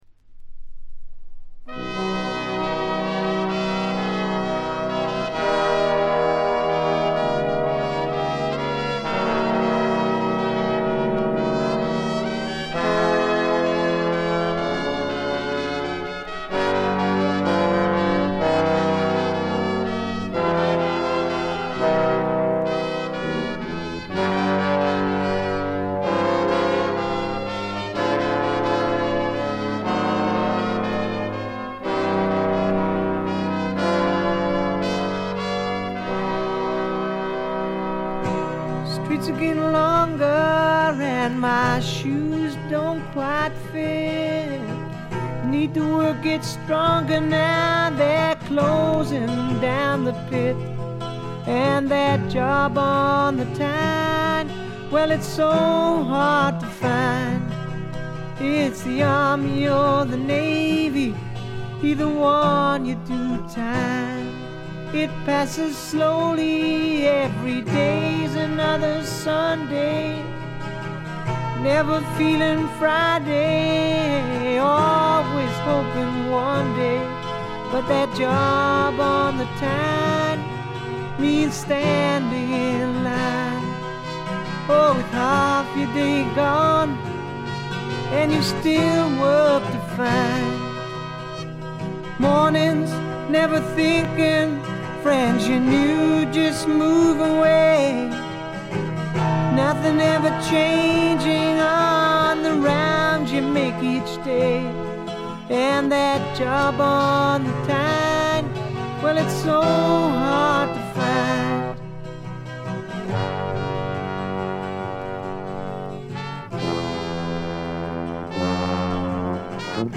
見た目に反してところどころでチリプチが出ますが大きなノイズはありません。
それに加えて激渋ポップ感覚の漂うフォークロック作品です。
この人の引きずるように伸びのあるヴォーカルは素晴らしいです。
試聴曲は現品からの取り込み音源です。
Recorded At - Stargroves